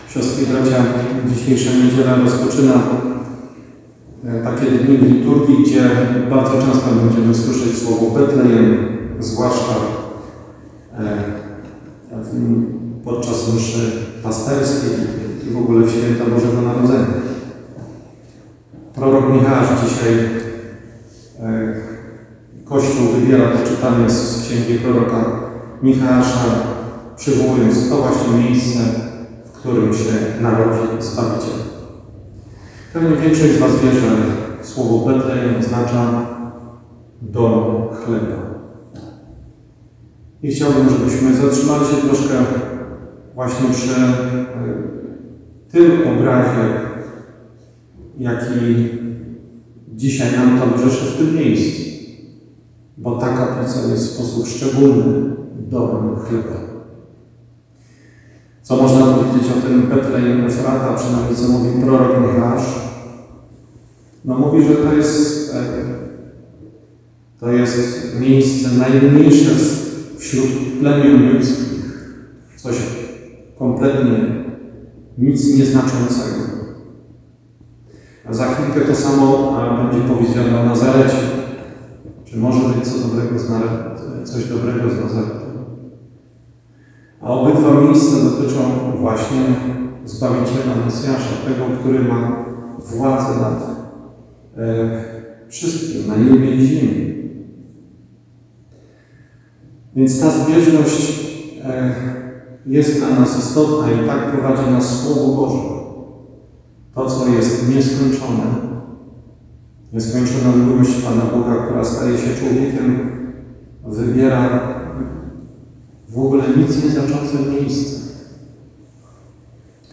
Msza święta w intencji Liderów Adoracji w Kaplicy Wieczystej Adoracji pw. św. Jana Pawła II w Jedlni - Kolonii - Fundacja Eucharystyczny Płomień